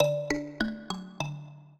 mbira
minuet15-9.wav